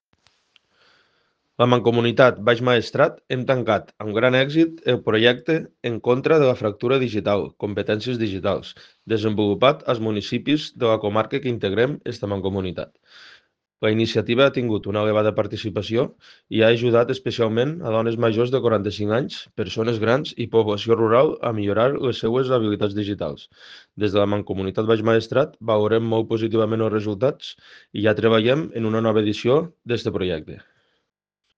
TALL-DE-VEU.-COMPETENCIES-DIGITALS-MANCOMUNITAT-BAIX-MAESTRAT.ogg